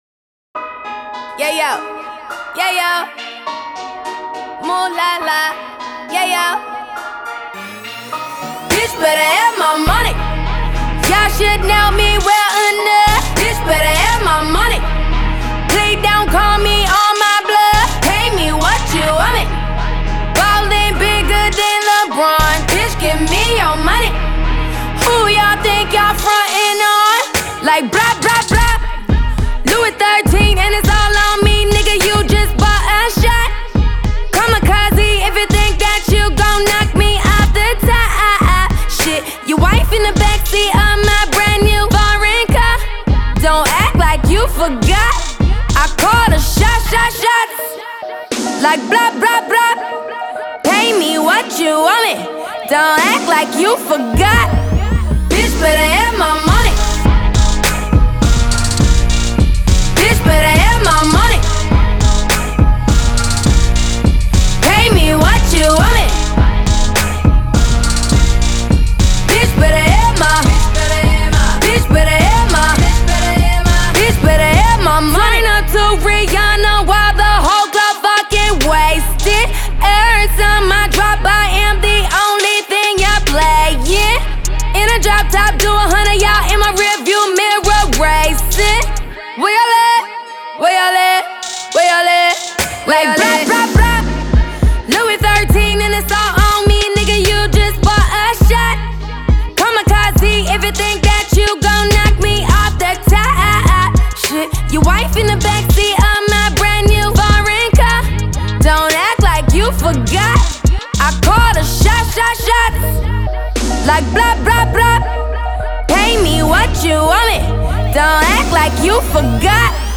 sing-songy rap style
dark, trap beat